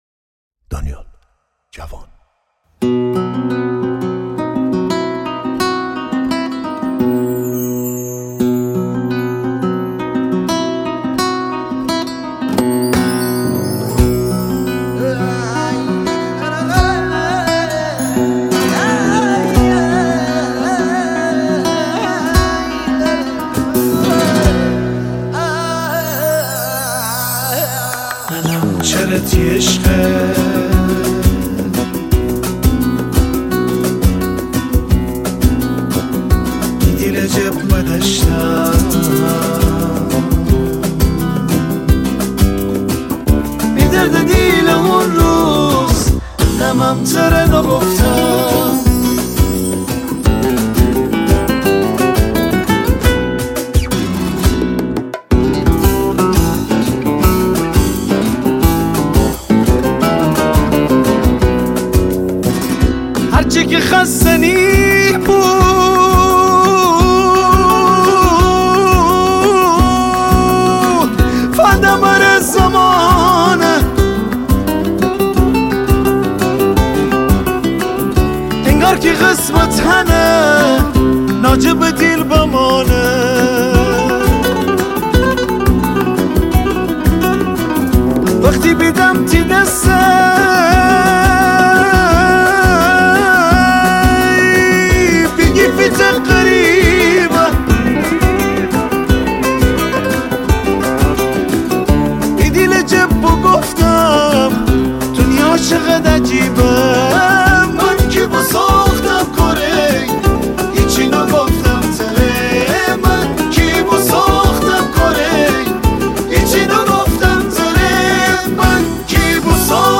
اهنگ گلکی شمالی